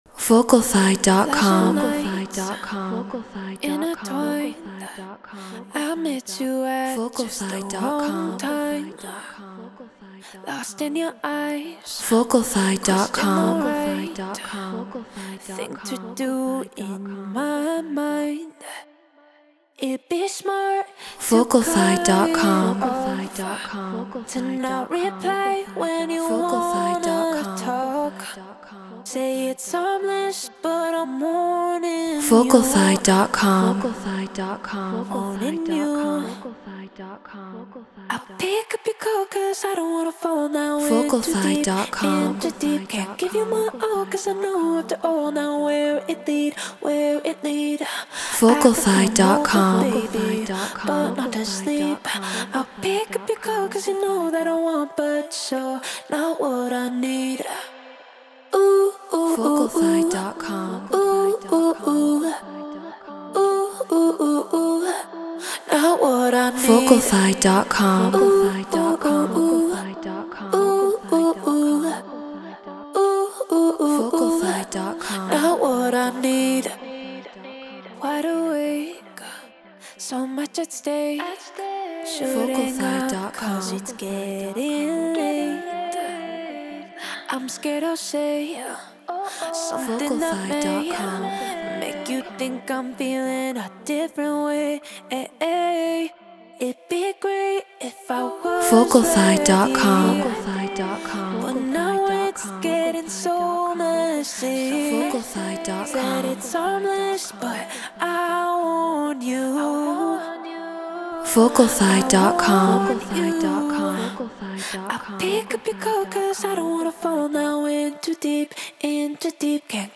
Non-Exclusive Vocal.